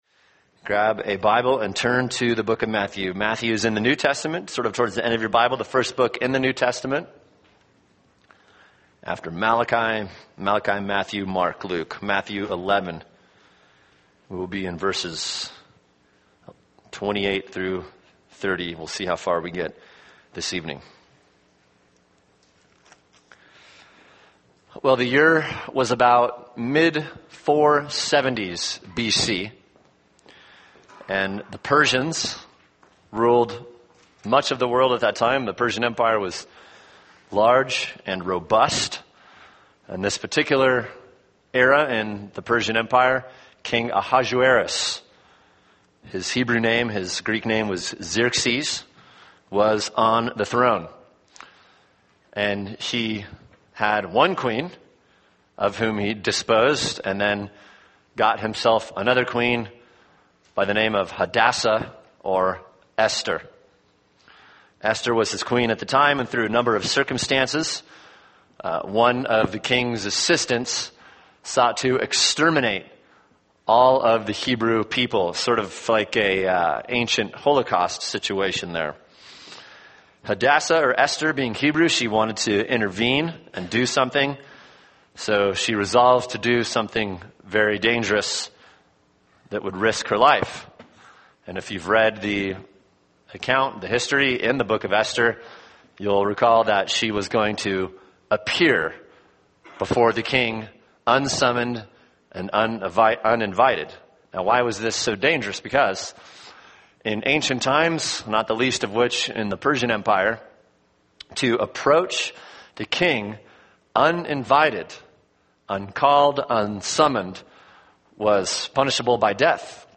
[sermon] Matthew 11:25-30 – God’s Sovereign Grace (part 2) | Cornerstone Church - Jackson Hole